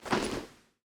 equip_leather4.ogg